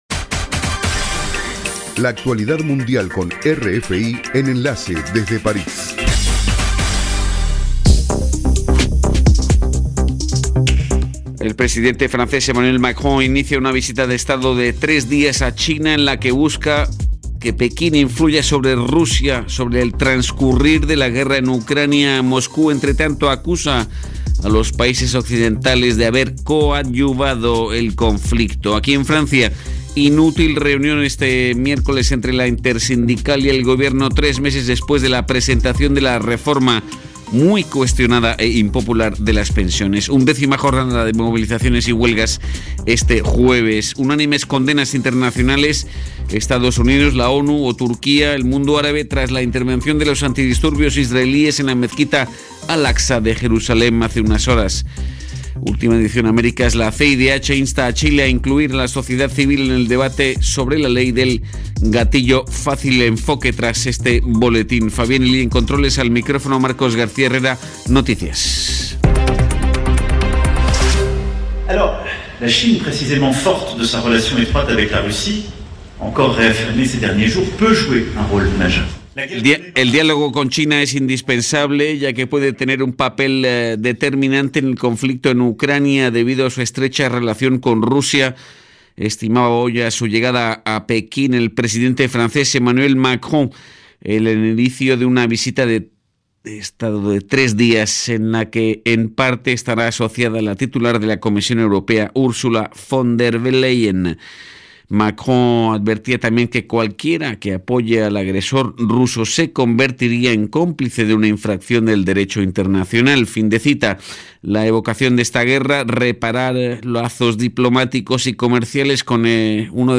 Programa: RFI - Noticiero de las 20:00 Hs.